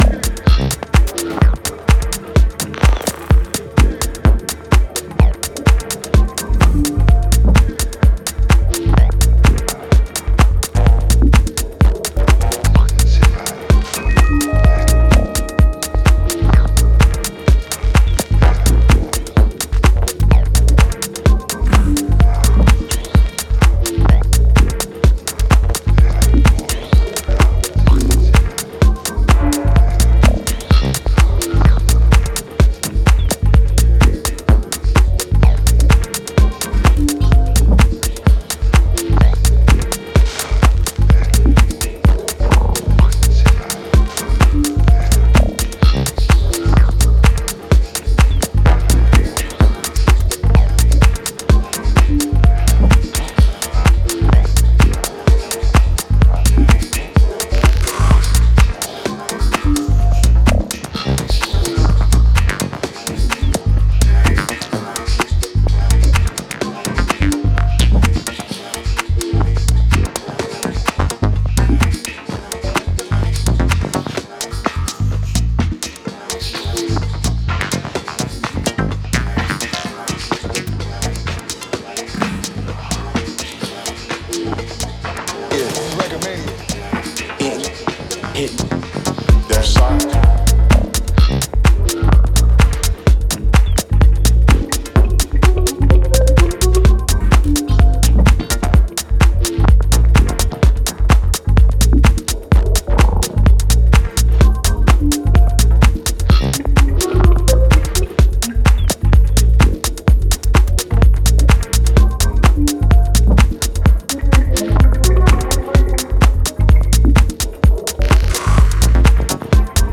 minimal house music